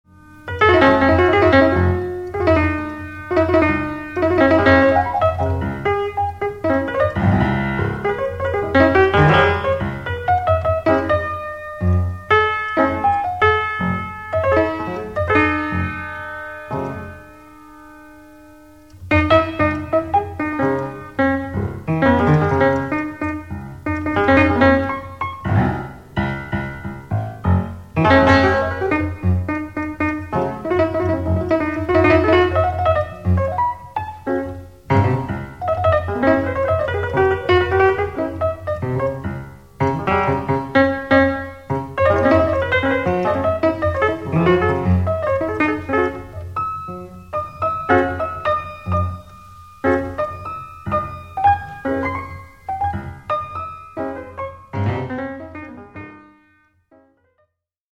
LIVE IN LONDON
PIANO SOLO